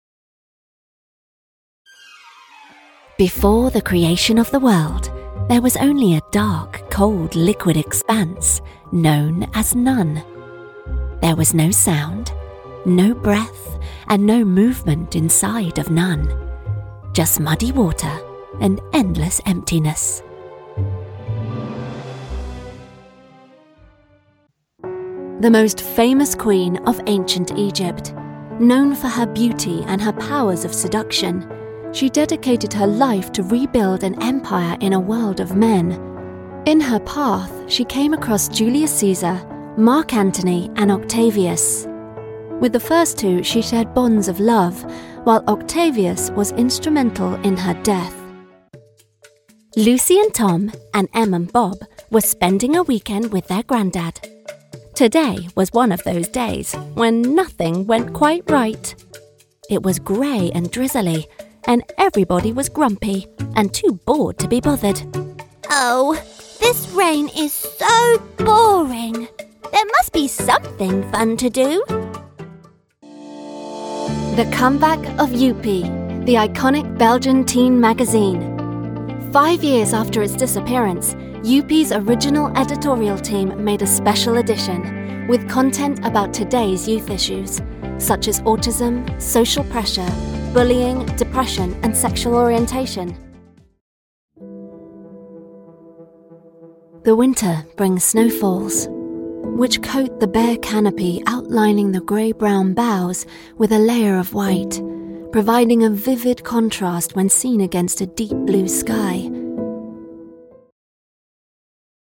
Narration Showreel
Female
Neutral British
Estuary English
Friendly
Soft
Warm
Youthful